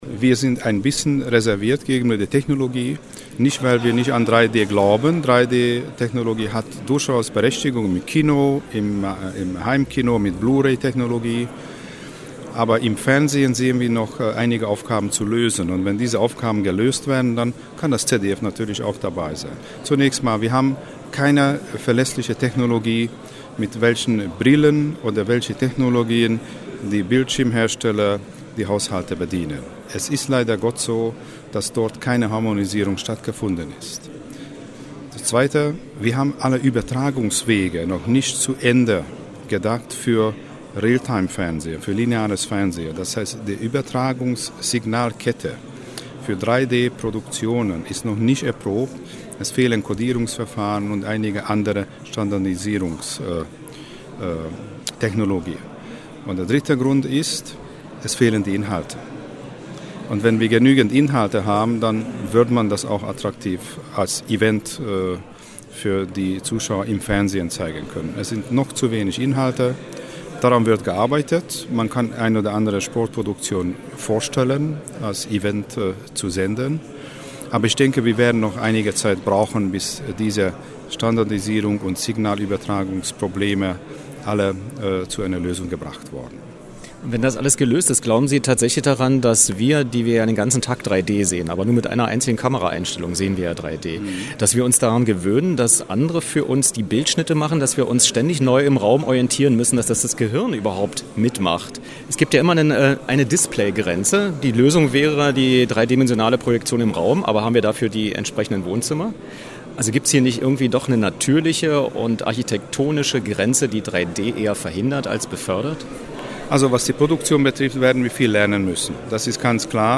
O-Töne von:
Was: Studiogespräch zu 3D nach 3 Jahren Markteinführung
Wo: Potsdam-Babelsberg, Medienstadt, radioeins-Sendestudio